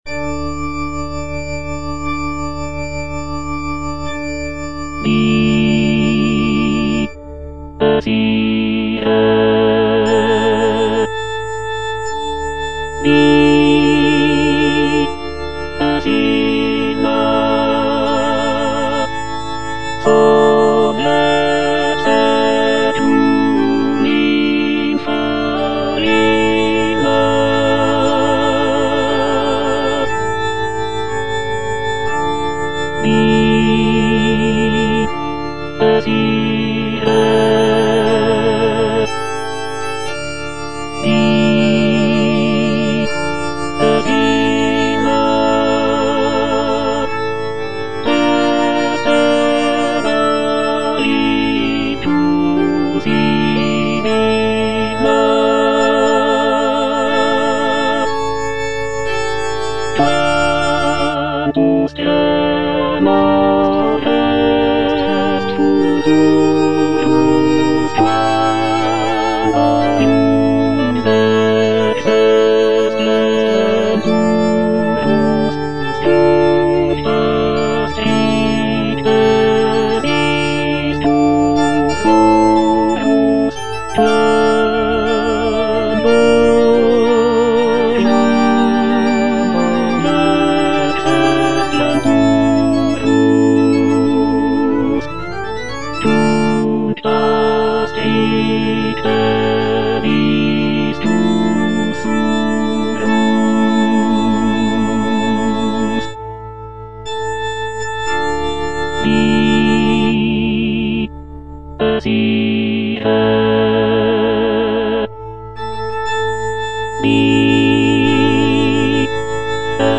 Tenor (Emphasised voice and other voices) Ads stop